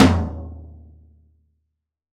Index of /90_sSampleCDs/AKAI S6000 CD-ROM - Volume 3/Drum_Kit/ROCK_KIT2
T TOM F 1B-S.WAV